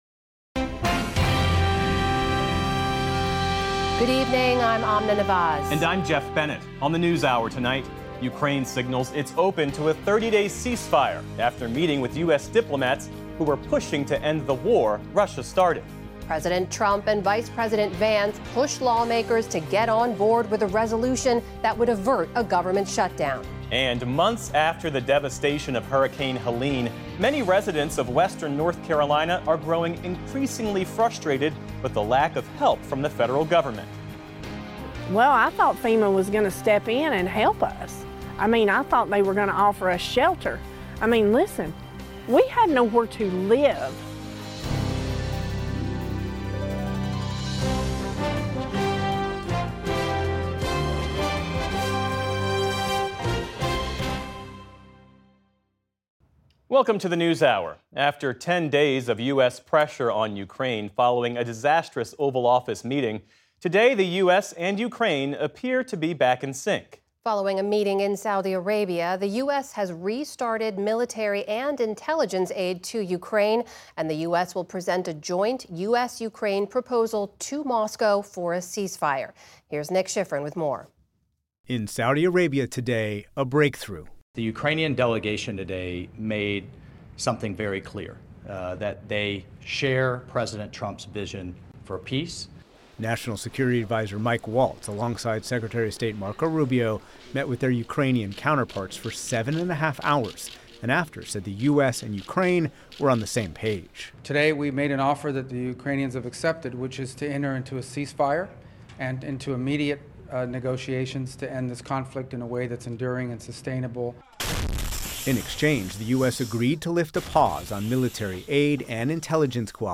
PBS NewsHour News, Daily News